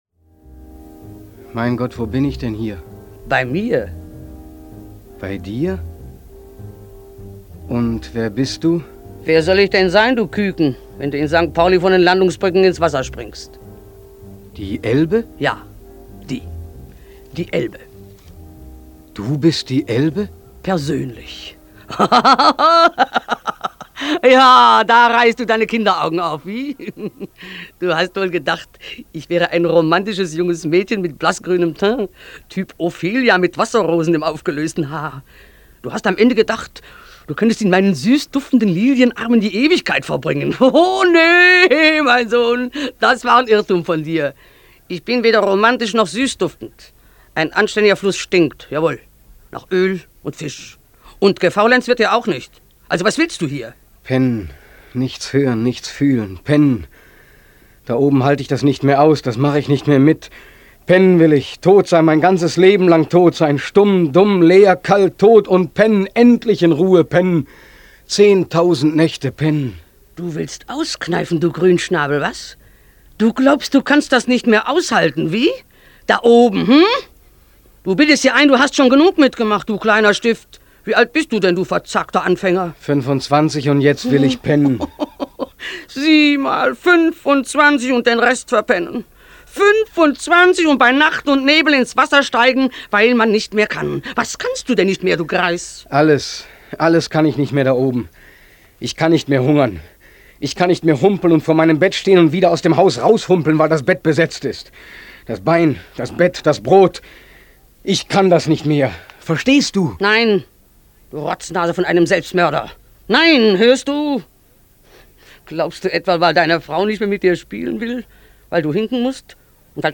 Als der Nordwestdeutsche Rundfunk es als Hörspiel am 13. Februar 1947 ausstrahlte, wurde das Drama zum überwältigenden Publikumserfolg - für Borchert und für das noch junge Radio der Nachkriegszeit.